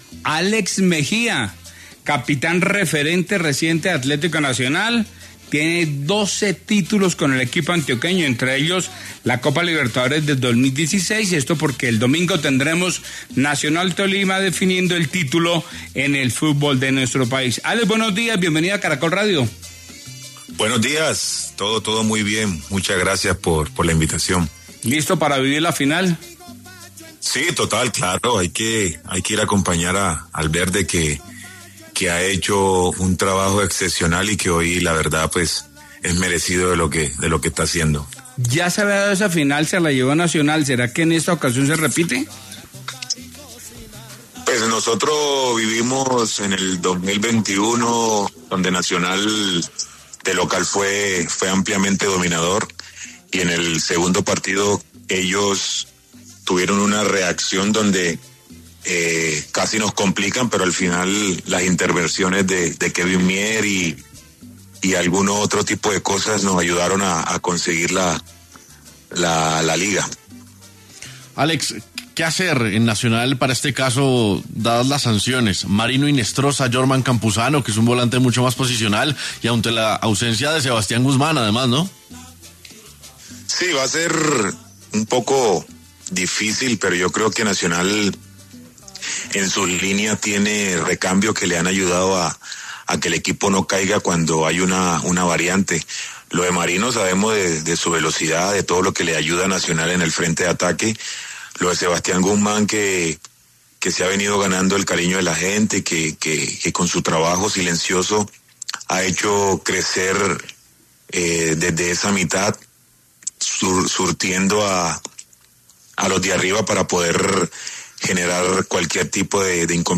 Álex Mejía habló en 6AM de Caracol Radio sobre el favoritismo que tiene Atlético Nacional en la gran final del fútbol colombiano, la cual afrontará con el Deportes Tolima en el Atanasio Girardot.